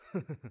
xys嘲弄1.wav 0:00.00 0:00.51 xys嘲弄1.wav WAV · 44 KB · 單聲道 (1ch) 下载文件 本站所有音效均采用 CC0 授权 ，可免费用于商业与个人项目，无需署名。
人声采集素材